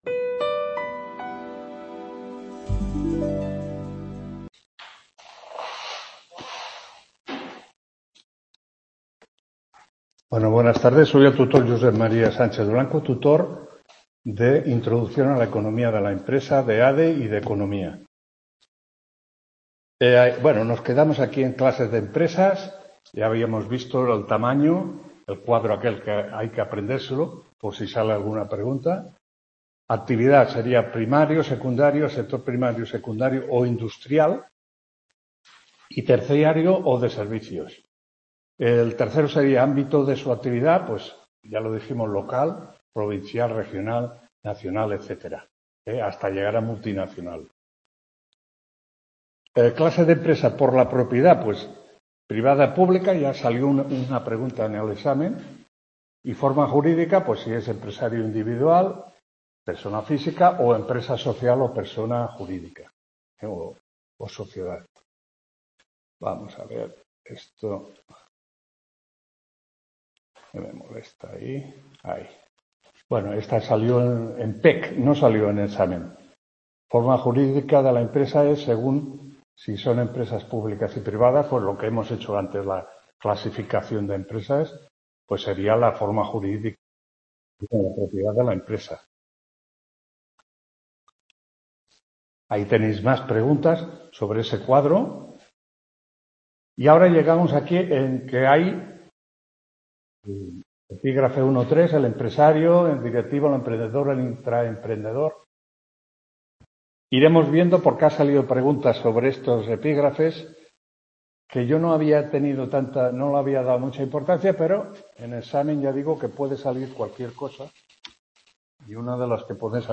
2ª TUTORÍA INTRODUCCIÓN A LA ECONOMÍA DE LA EMPRESA 15… | Repositorio Digital